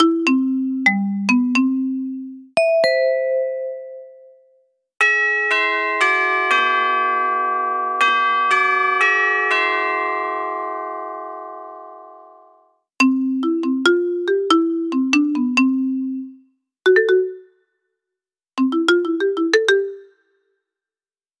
set campanelle senza fili, gamma=150m, bi spina Euro flash LED
set campanelle / emissione sonora: senza fili / gamma: 150m / volume regolabile fino a 84dB / 6 melodie / colore: bianco / contenuto: pulsante radio gong e campanello DCP911 (inkl. 1xCR2032) / funzioni: segnale visivo e acustico, funzione di ricarica USB per dispositivi esterni
Melodien_128329.mp3